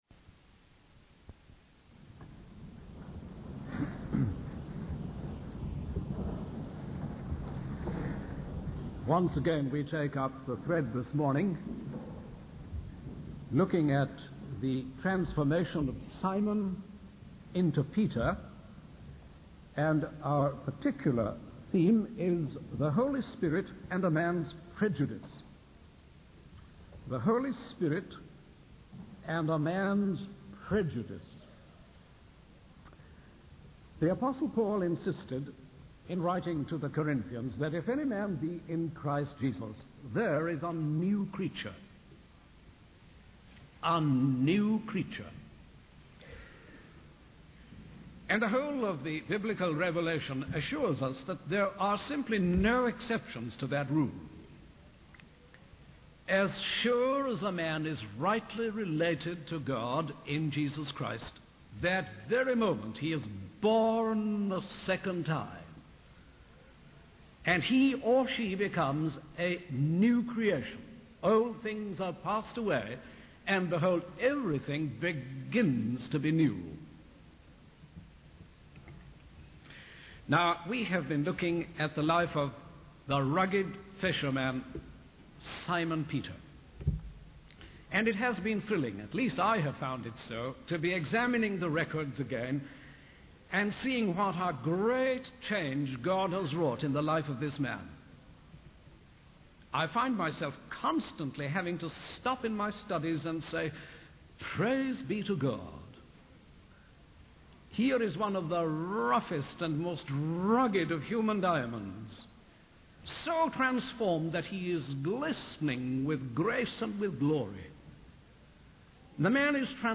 In this sermon, the preacher focuses on the transformation of Simon Peter and how God dealt with his prejudice. The preacher emphasizes that when a person is in Christ, they become a new creation, and old things pass away. The sermon highlights the ugliness of prejudice and how it goes against God's will.